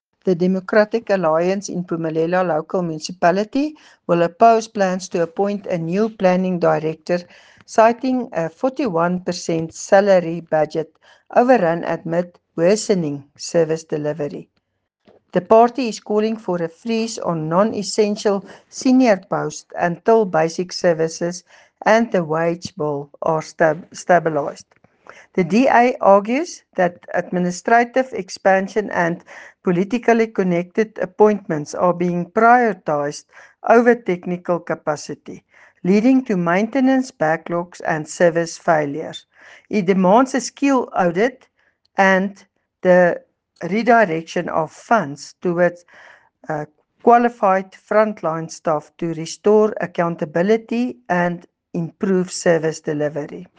Afrikaans soundbites by Cllr Doreen Wessels and